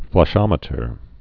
(flŭsh-ŏmĭ-tər)